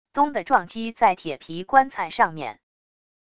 咚的撞击在铁皮棺材上面wav音频